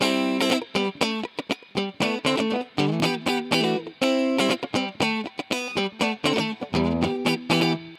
23 Guitar PT4.wav